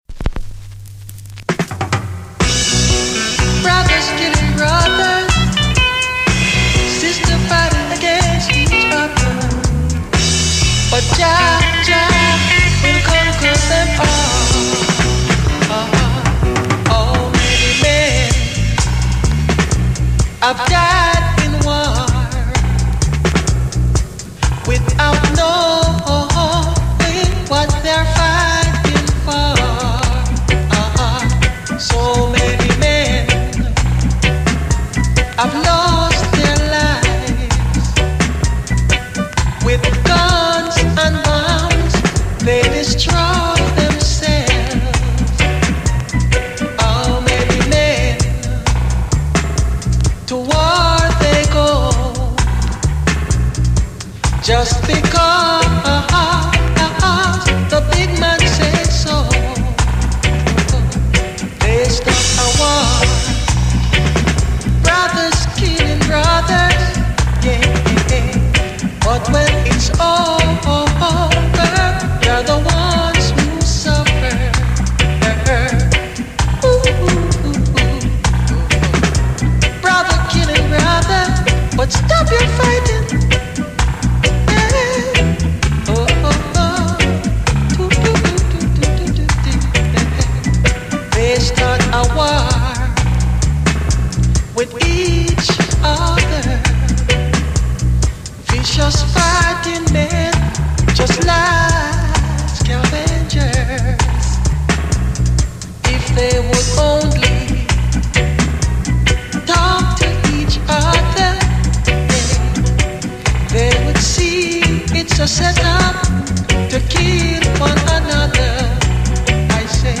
Strictly Vinyl